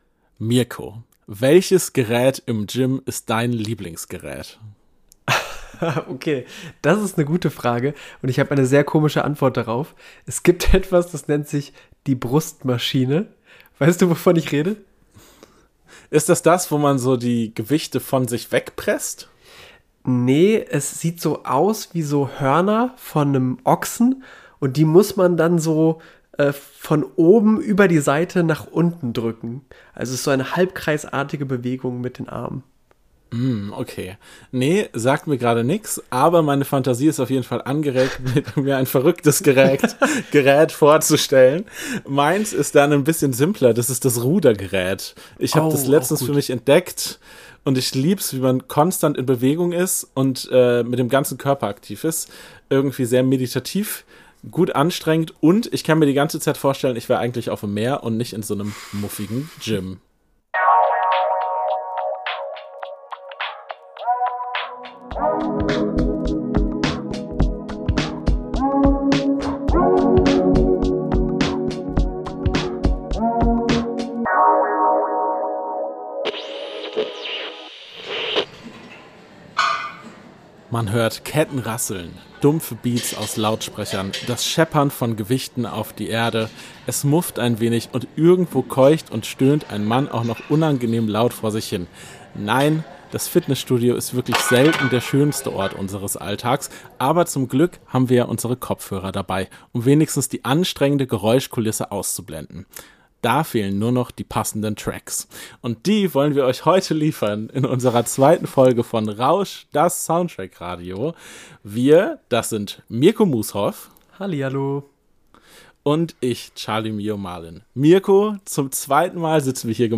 Man hört Ketten rasseln, dumpfe Beats aus Lautsprechern, das Scheppern von Gewichten auf die Erde, es mufft ein wenig und irgendwo keucht und stöhnt ein Mann auch noch unangenehm laut vor sich hin.